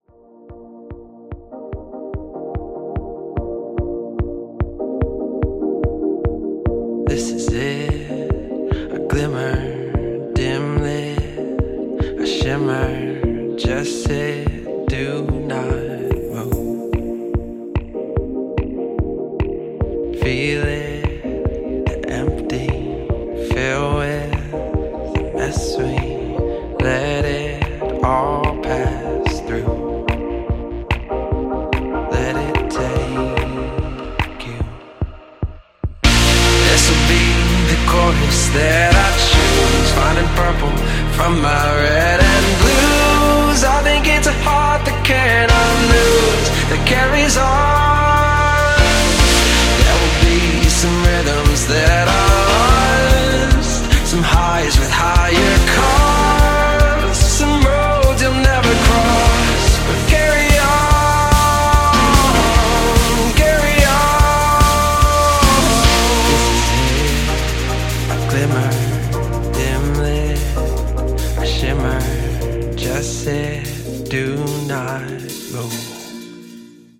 BPM146
Audio QualityLine Out